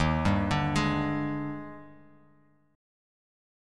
D#M11 Chord
Listen to D#M11 strummed